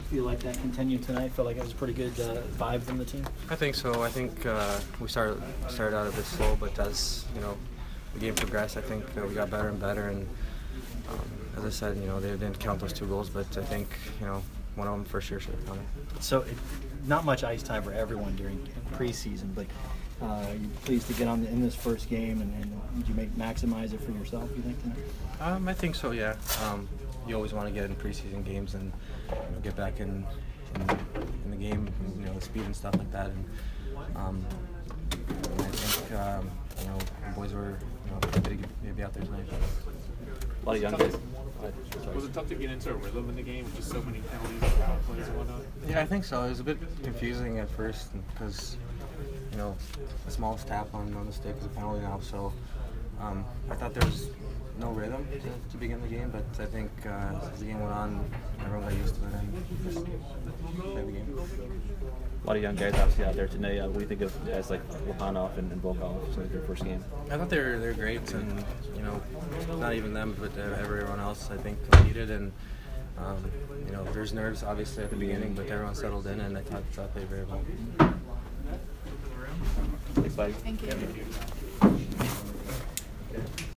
Vladislav Namestnikov Postgame